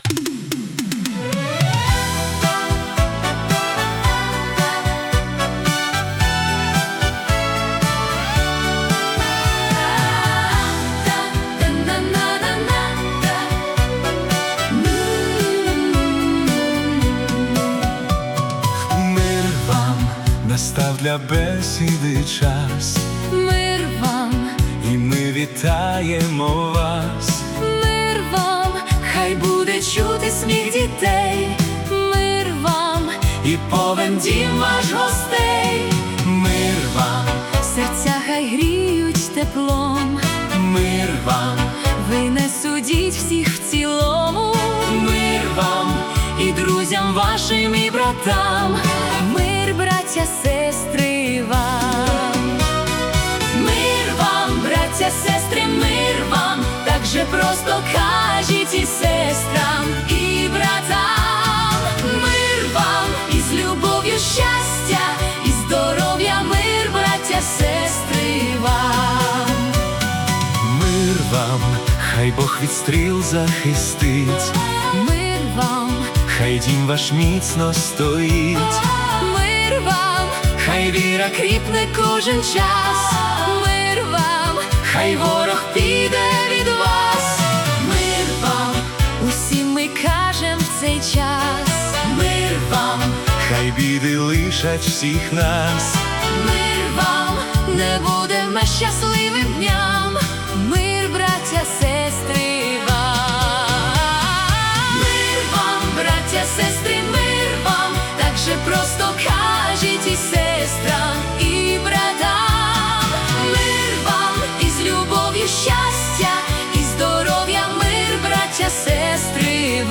Тепле вітання у стилі золотої християнської естради 🎶
Це християнська музика, яка лікує тривоги і дарує надію.